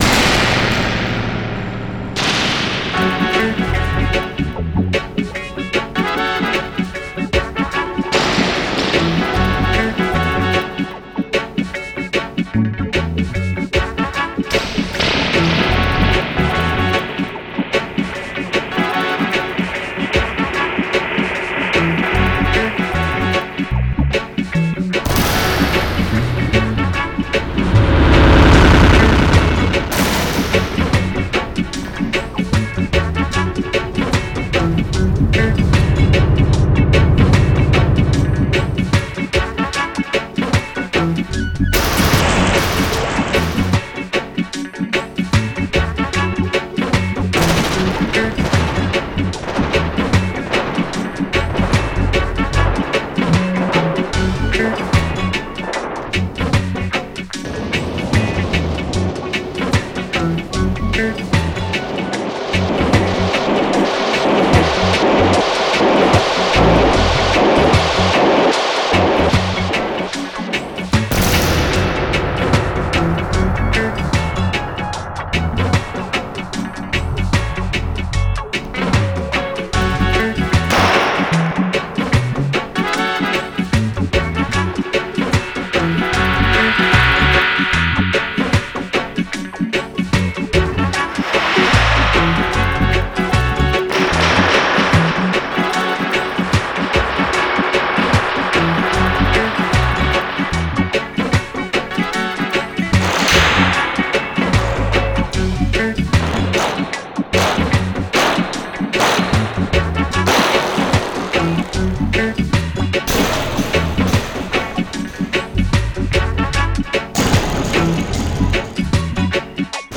雷鳴のようなダブエコーと実験的サウンドデザイン – クラシックダブの真のサウンド
各タンクスラムは手作業で制作され、5つのリバーブタンクを揺らす、叩く、つま弾く、落とすといった方法で唯一無二で独占的なワンショットやヒットを生み出しました。
2つのミディアムタンク – タイトでパーカッシブなスラムに最適です。
2つのラージタンク – 深く、雷鳴のようなヒットと長い残響を持ちます。
Gritty – 歪んでザラついた音質です。
LoFi – 暖かく劣化した質感です。
Phaser – 渦巻くようなサイケデリックな動きです。
Genre:Dub
1100 Spring Reverb Tank FX One shots